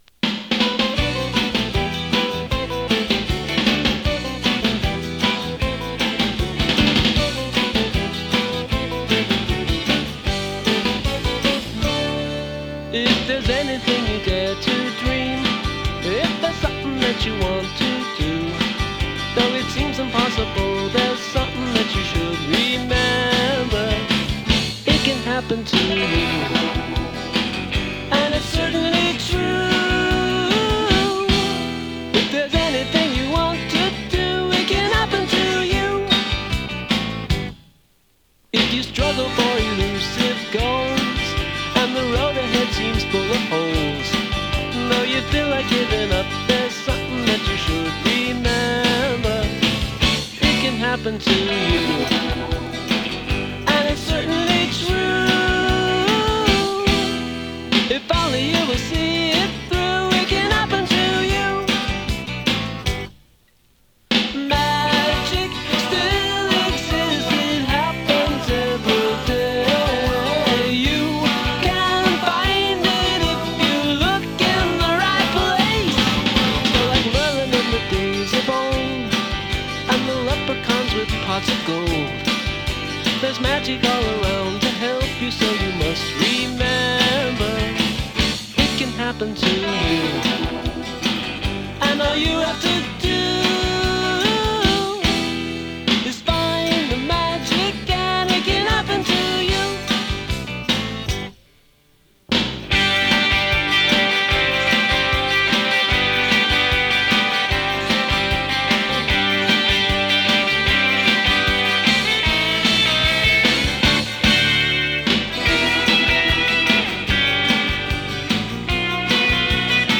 ラヴリーパワーポップ
インディーポップ